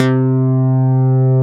Index of /90_sSampleCDs/Roland L-CDX-01/BS _Synth Bass 1/BS _MIDI Bass